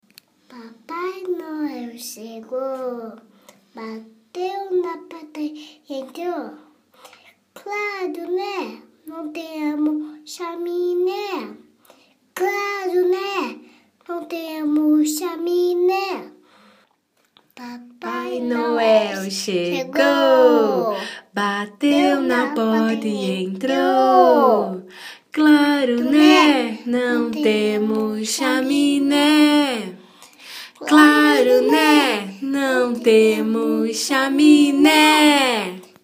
Deixa eu já avisar que são paródias divertidas.
A música é uma marchinha de Carnaval, escuta só: